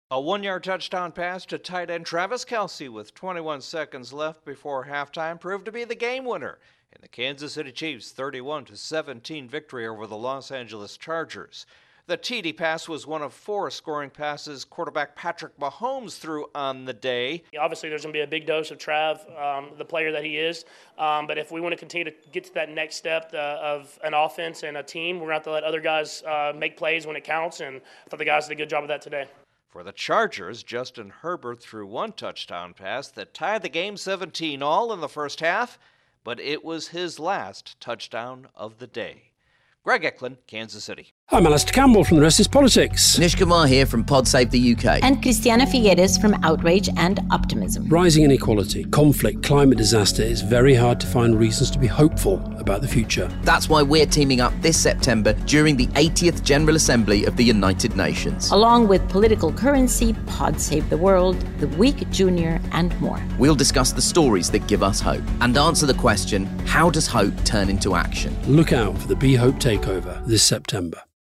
Patrick Mahomes is on target as the Chiefs down the Chargers. Correspondent